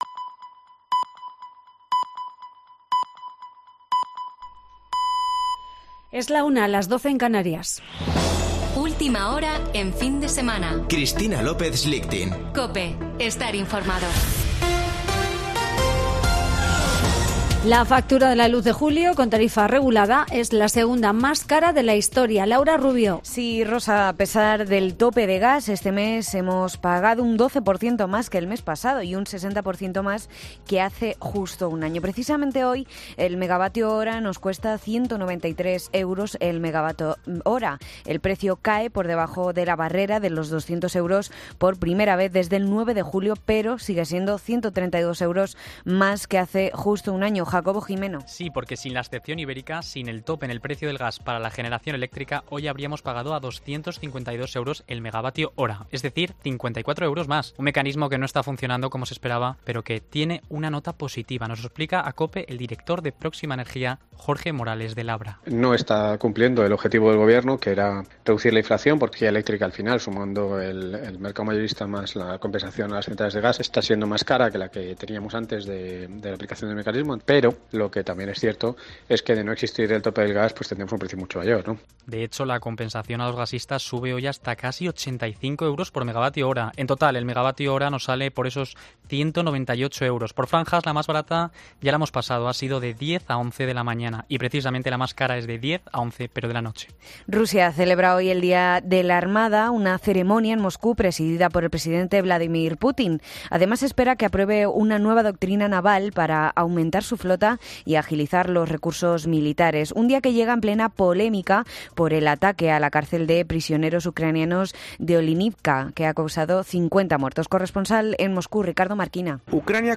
Boletín de noticias de COPE del 31 de julio de 2022 a la 13.00 horas